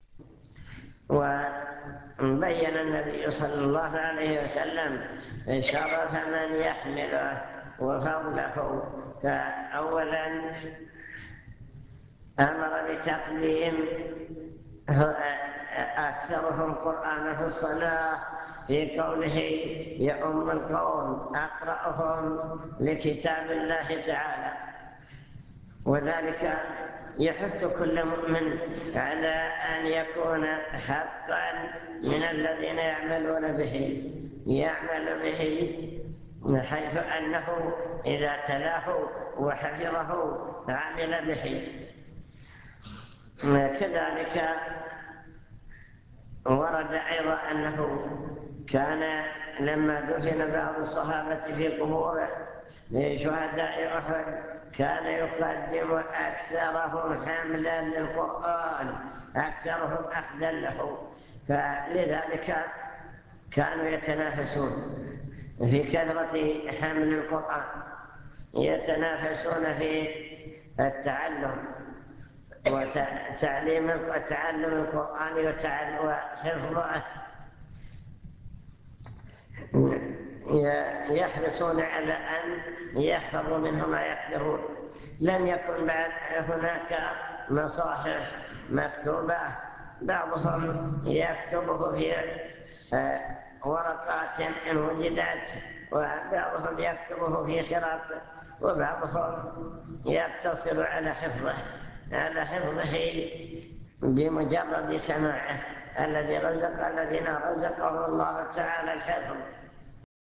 المكتبة الصوتية  تسجيلات - محاضرات ودروس  محاضرة عن القرآن والسنة